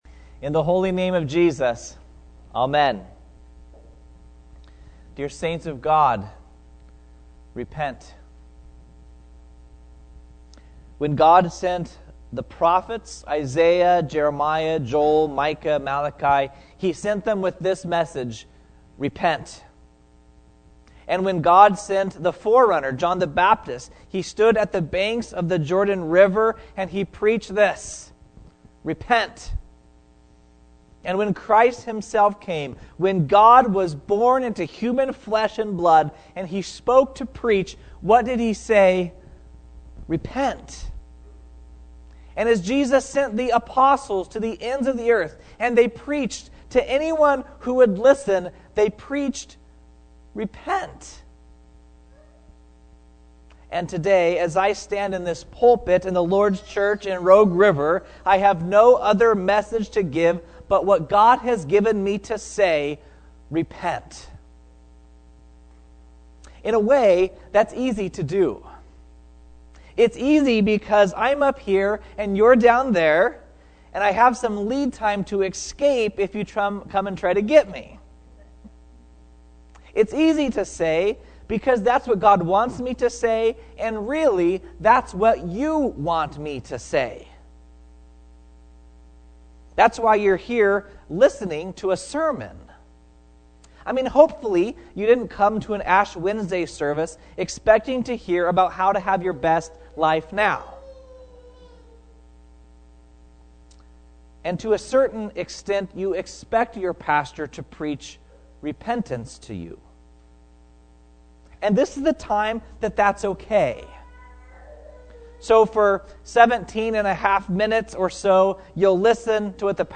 2018-ashwed.mp3